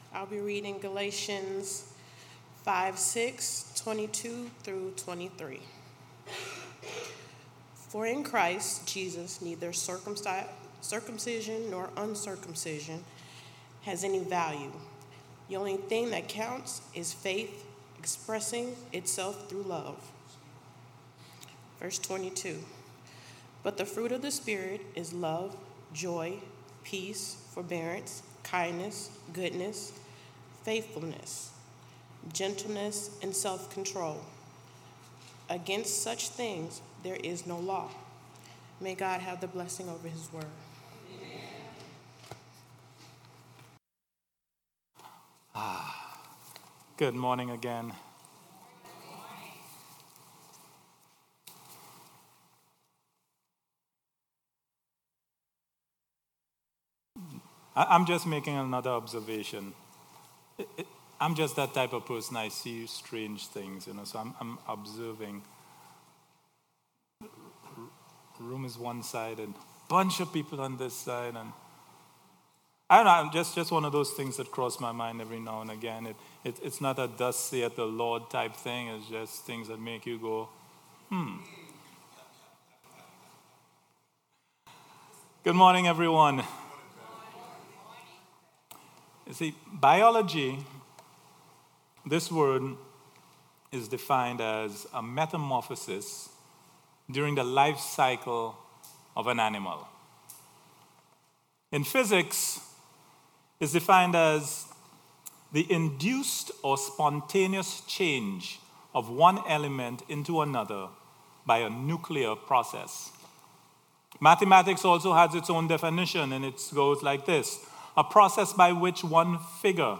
Worship Service 6/3/18